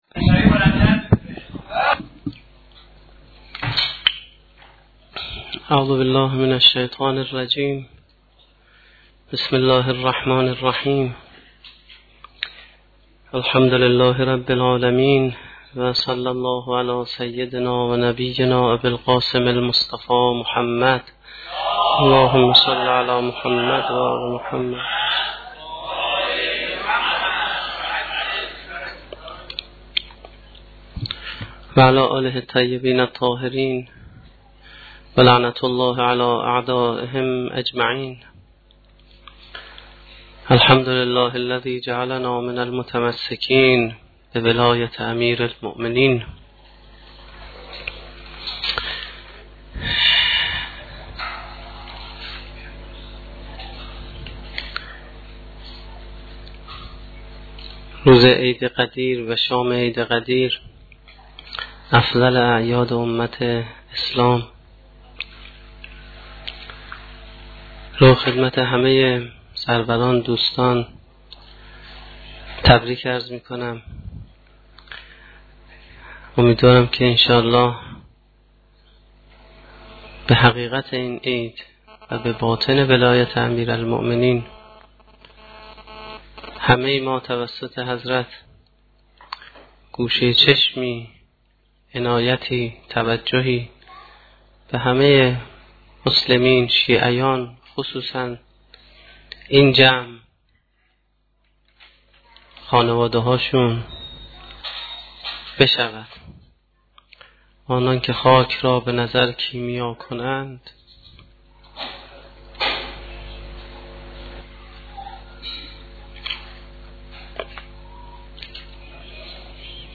در شام عید سعید غدیرخم با تفسیر بعضی از آیات سوره ی طه به تبیین مفهوم ولایت و حقیقت نبوت و امامت پرداخته و مطالبی را در بیان حقانیت حضرت علی بیان فرمودند